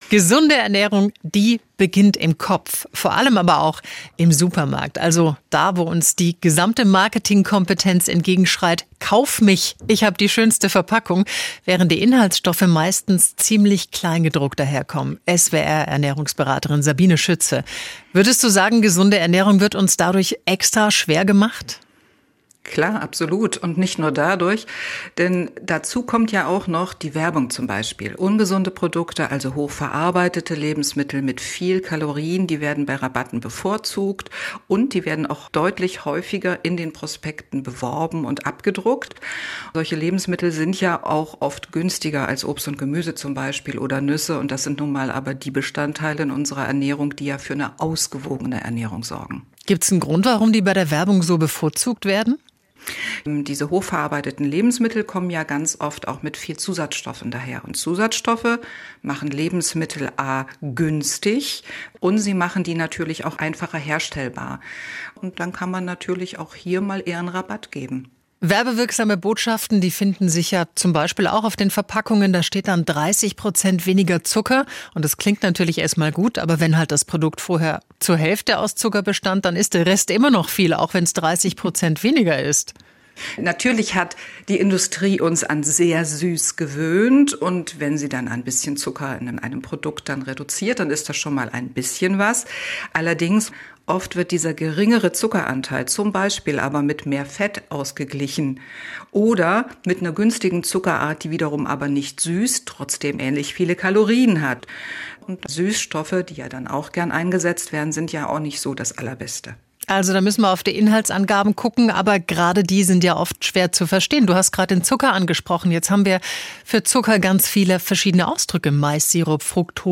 Gesund durch den Supermarkt - Gespräch